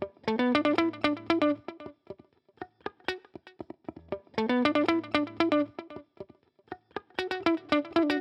11 Pickin Guitar PT3.wav